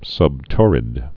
(sŭb-tôrĭd, -tŏr-)